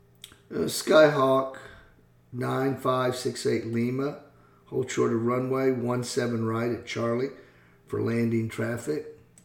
Aviation Radio Calls
09a_TowerRunwayHoldShortOneSevenRrightCharllieLandingTraffic.mp3